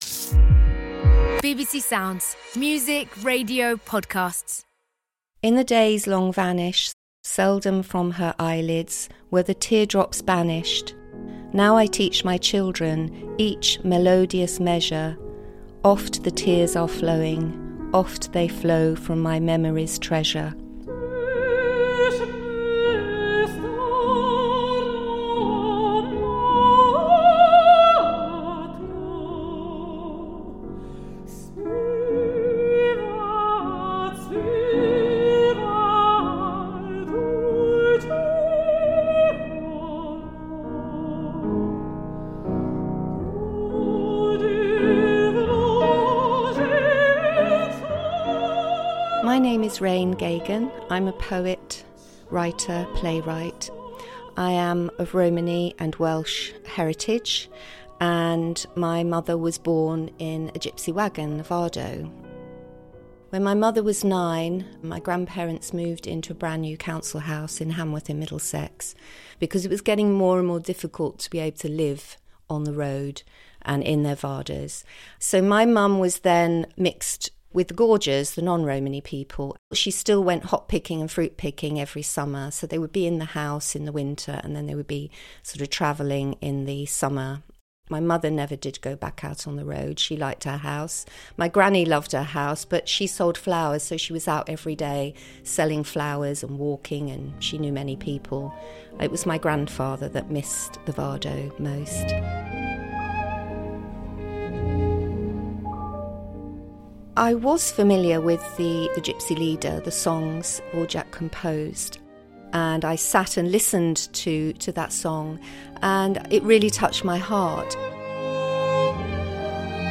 Live recording